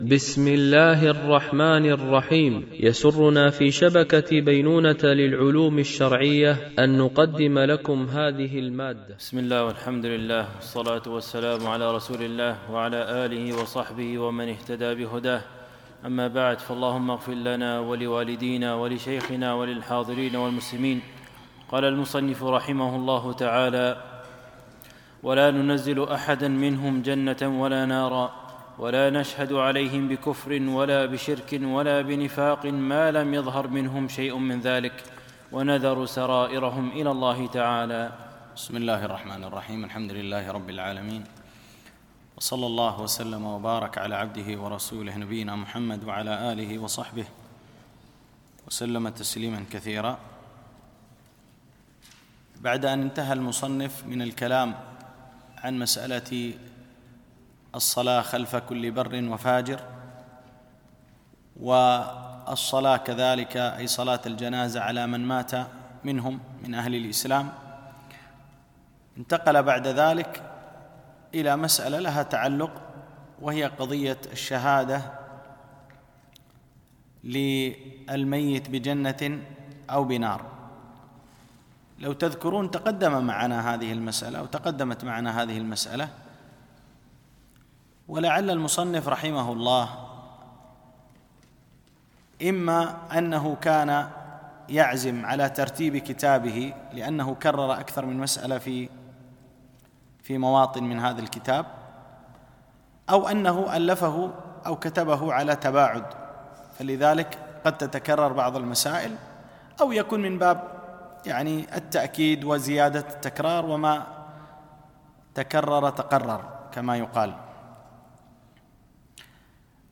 مباحث إيمانية - الدرس 10